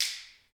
Index of /90_sSampleCDs/Roland L-CDX-01/PRC_Clap & Snap/PRC_Snaps